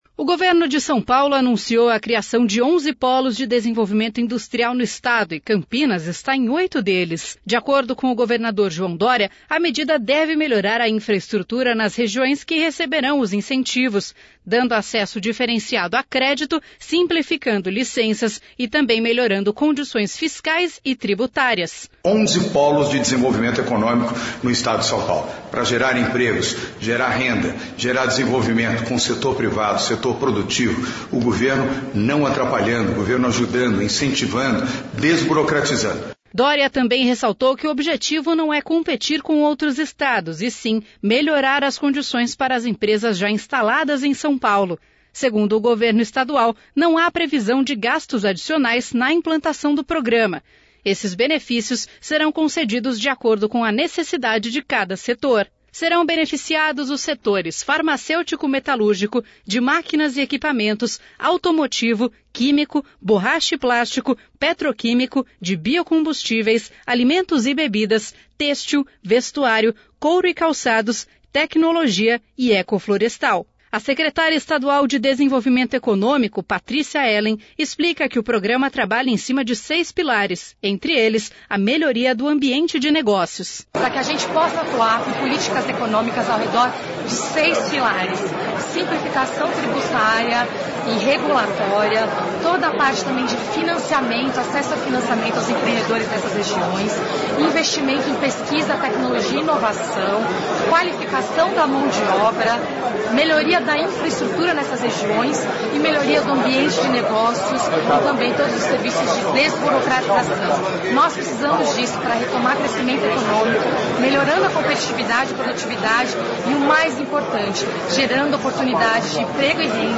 A Secretária Estadual de Desenvolvimento Econômico, Patrícia Ellen, explica que o programa trabalha em cima de seis pilares, entre eles, a melhoria do ambiente de negócios.